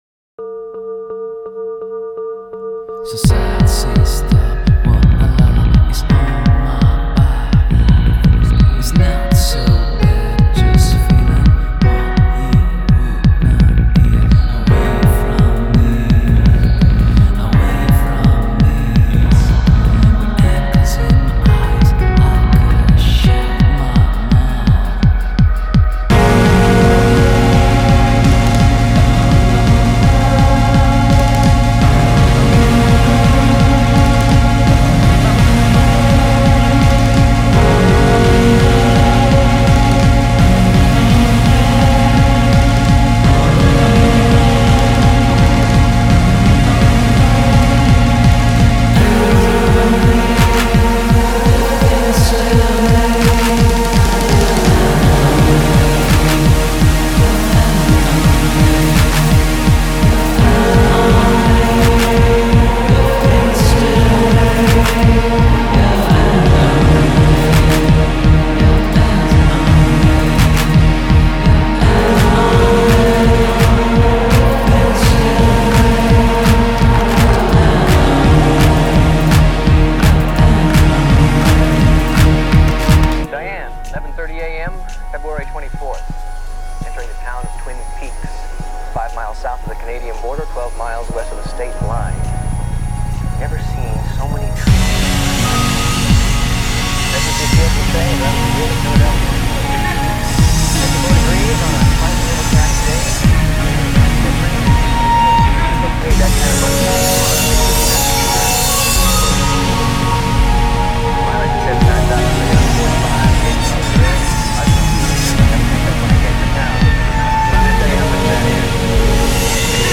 atmosfere sinistre